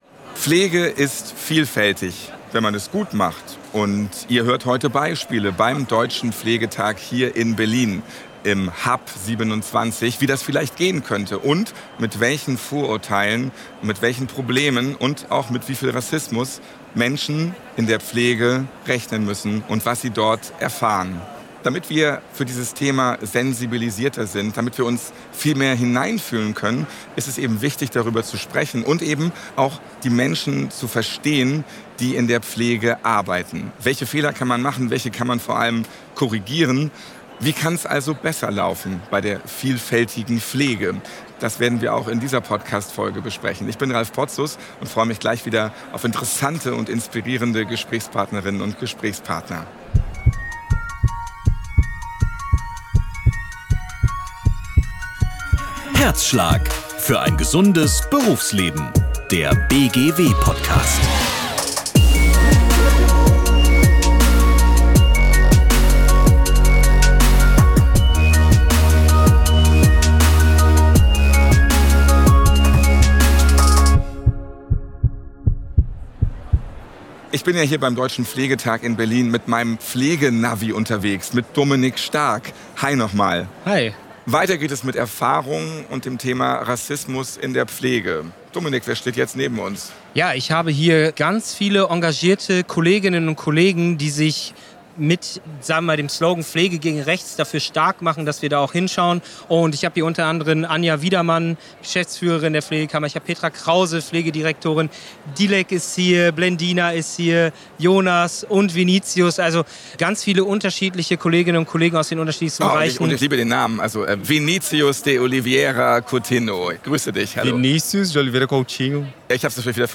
Im zweiten Teil unserer Reportage vom Deutschen Pflegetag in Berlin kommen erneut Stimmen aus der Pflege zu Wort. Es geht um ihren Arbeitsalltag, ihre Herausforderungen und ihre Haltung.